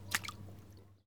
blood5.ogg